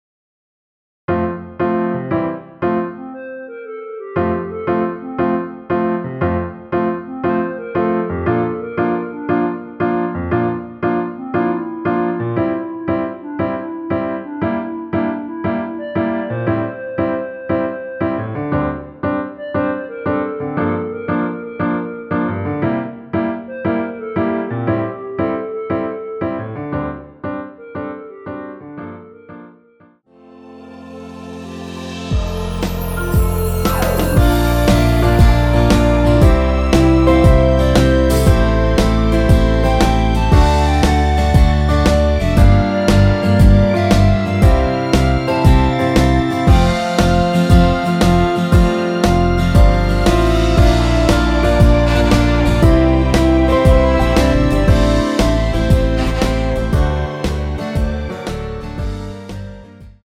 전주 없이 시작하는 곡이라 전주 만들어 놓았습니다.(미리듣기 참조)
원키에서(-1)내린 멜로디 포함된 MR입니다.
앞부분30초, 뒷부분30초씩 편집해서 올려 드리고 있습니다.